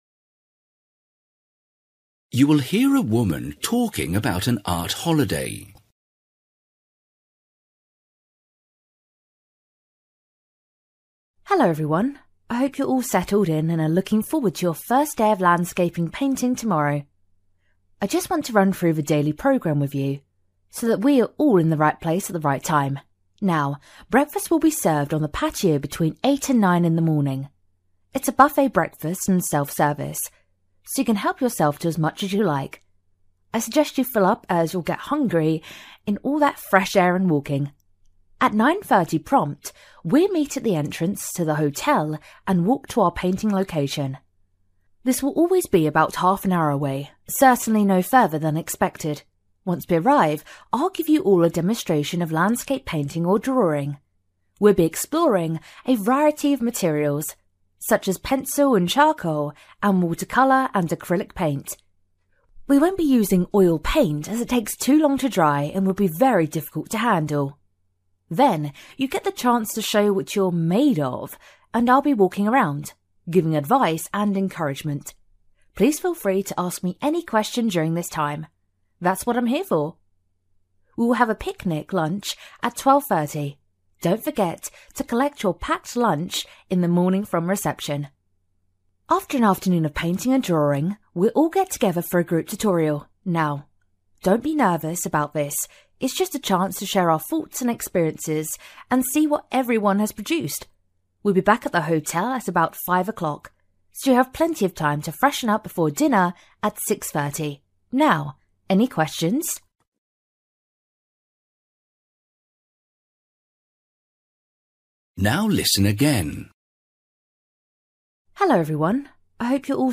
You will hear a woman talking about an art holiday.